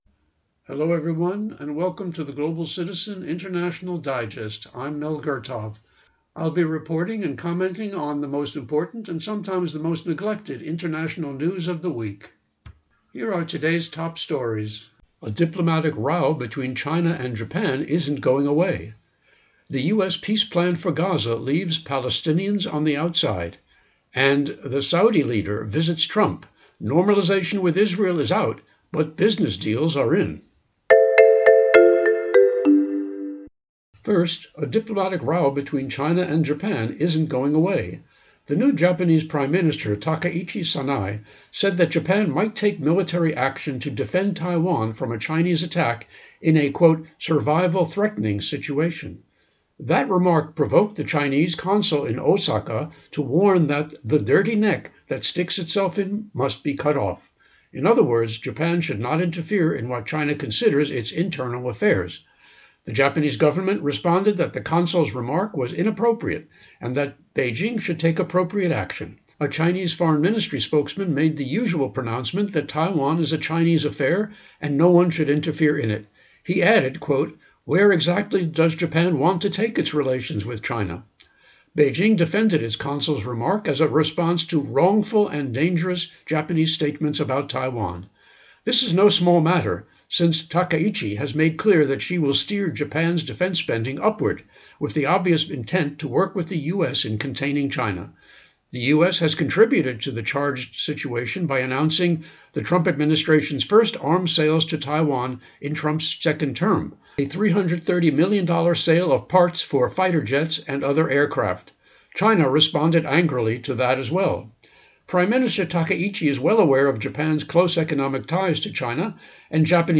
Public Affairs